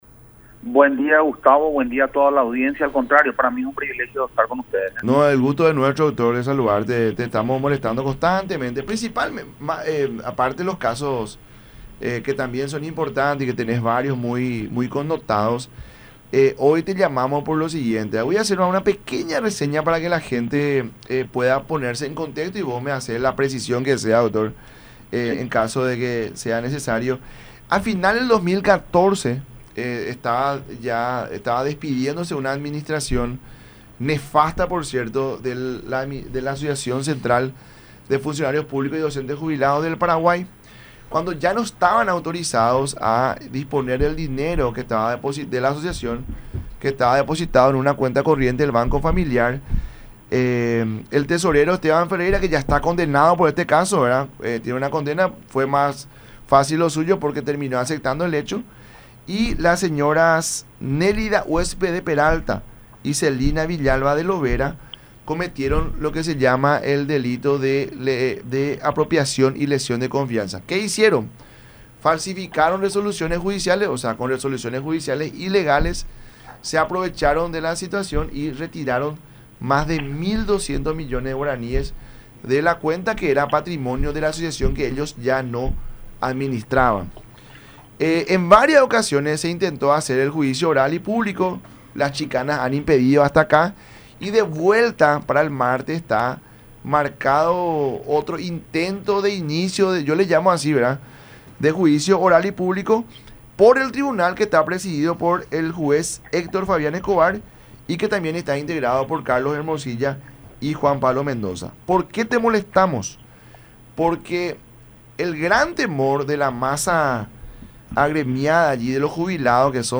El abogado reflejó su preocupación por la posible prescripción del caso.